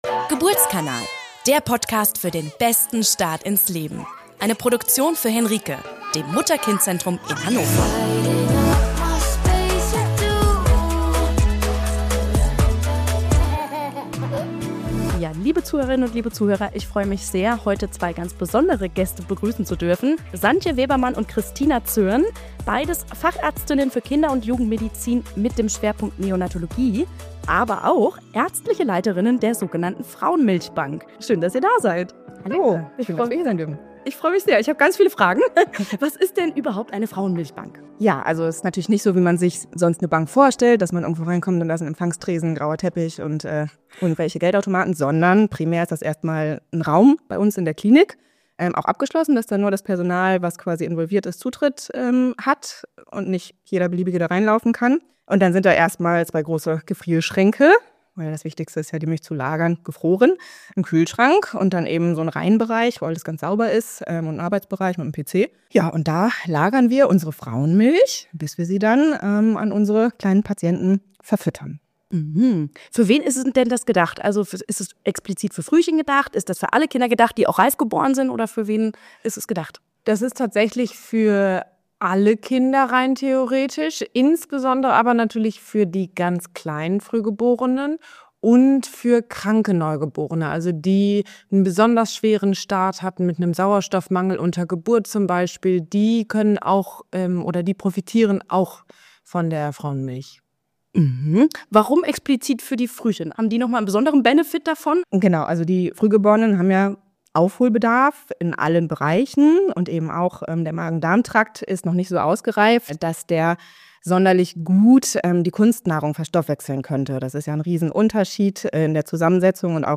Mit viel Herzblut erzählen unsere beiden Gäste vom Alltag in der Milchbank, der aufwendigen Qualitätskontrolle, dem Spendenprozess und der besonderen Beziehung zwischen Spenderinnen, Frühchen-Eltern und dem medizinischen Team.